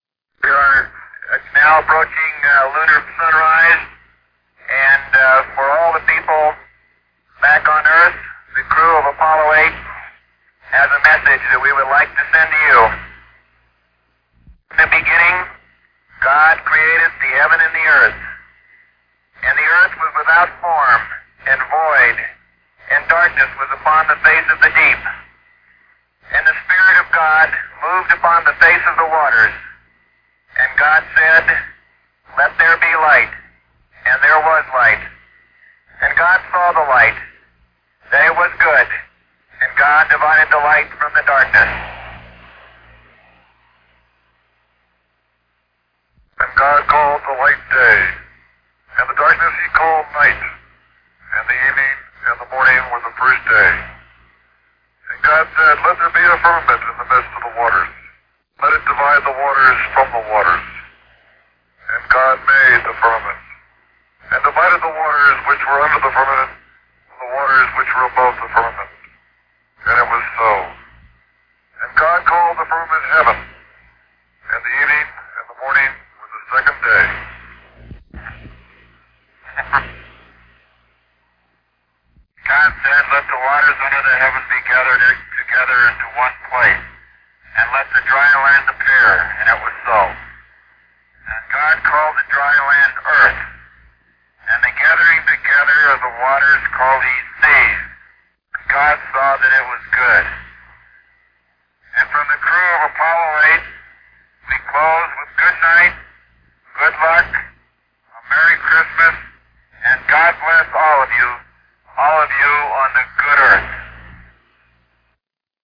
Launched in December 1968, Apollo 8 was the first human voyage to leave Earth's orbit and reach the vicinity of the Moon. Recognizing the historical gravity of the moment, the astronauts—William Anders, James Lovell, and Frank Borman—took turns reciting the first ten verses of the Book of Genesis during a Christmas Eve broadcast.
genesis.reading.mp3